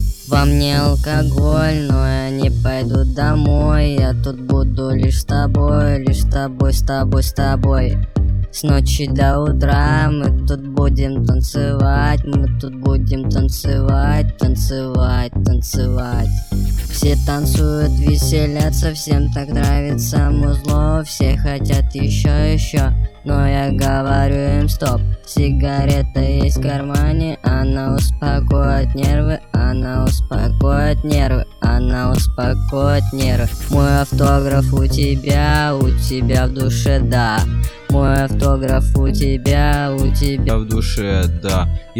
• Качество: 320, Stereo
спокойные